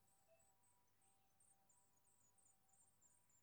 し ばらくすると雛は2〜3重の震わした音を出せるようになる。